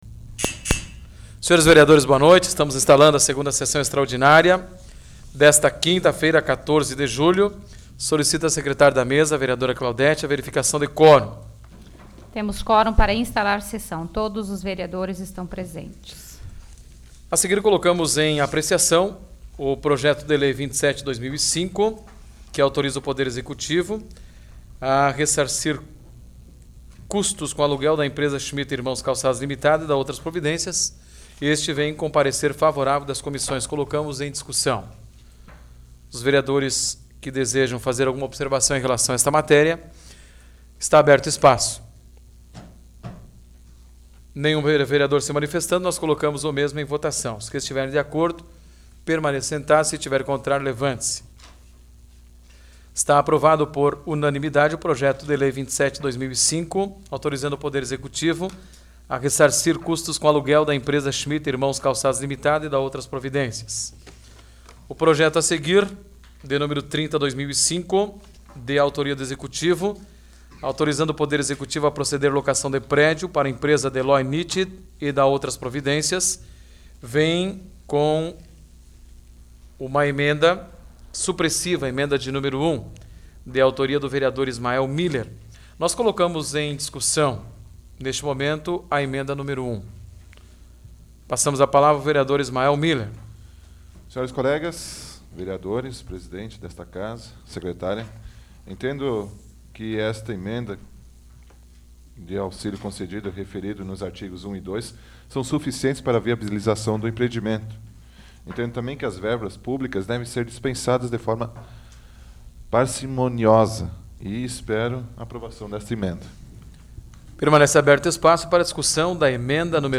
Áudio da 8ª Sessão Plenária Extraordinária da 12ª Legislatura, de 14 de julho de 2005